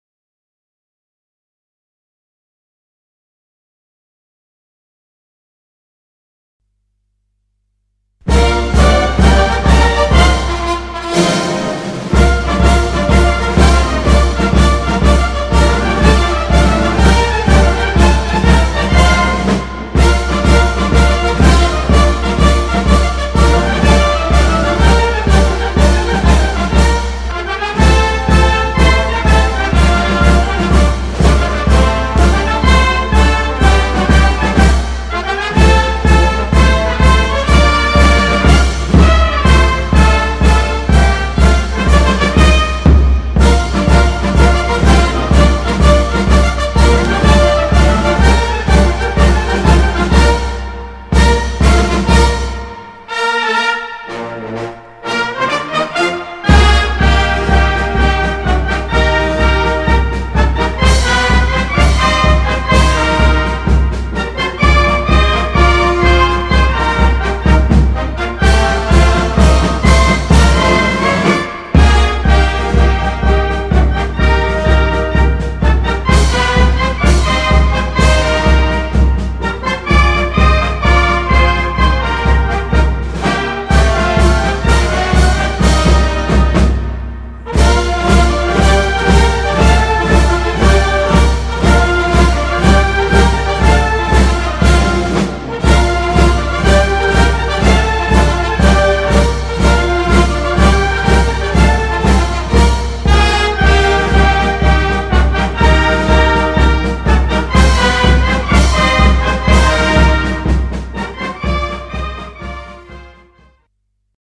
298 R U.S.A. parad March G. BESSON Marche 🖼